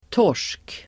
Uttal: [tår_s:k]